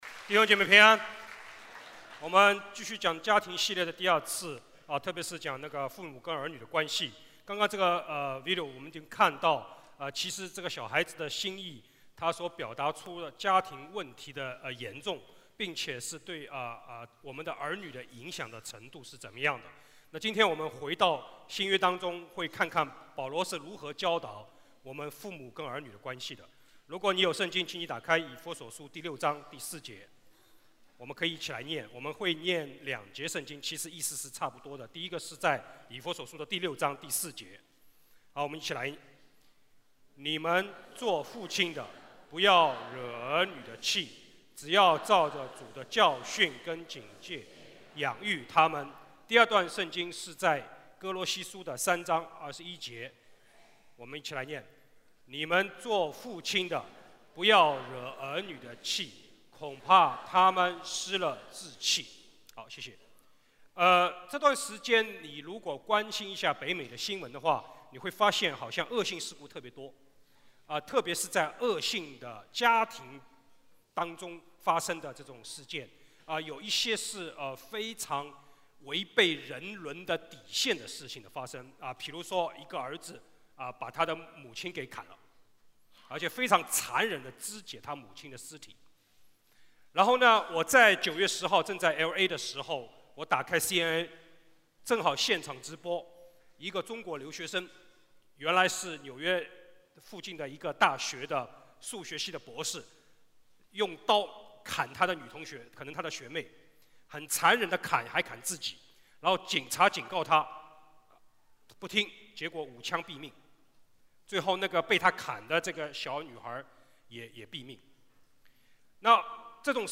主日证道 | 家庭系列之二：父母与儿女（二）